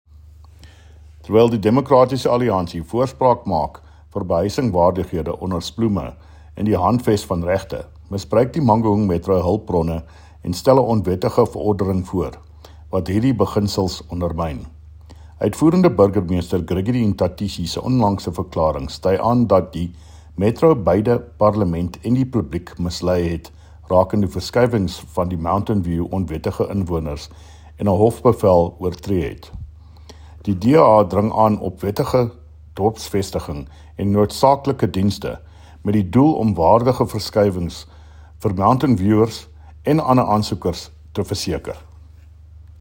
Afrikaans soundbites by David Mc Kay MPL, and